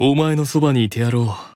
Character Voice Files